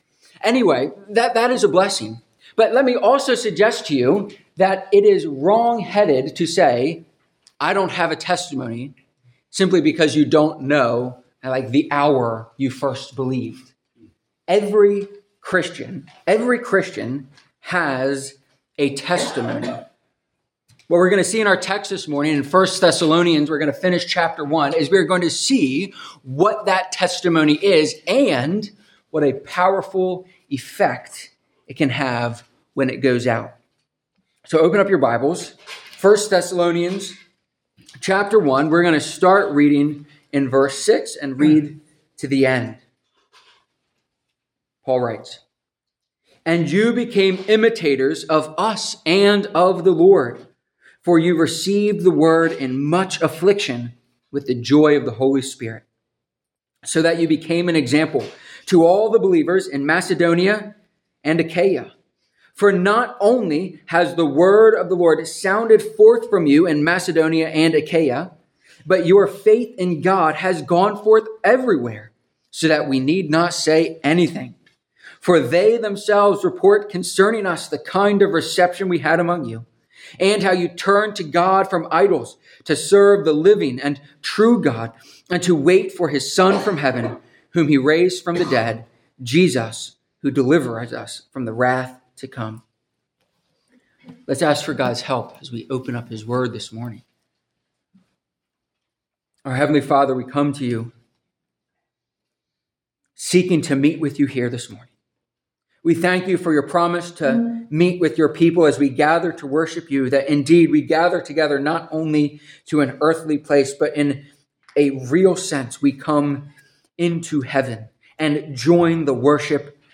3-2-Sermon-audio.mp3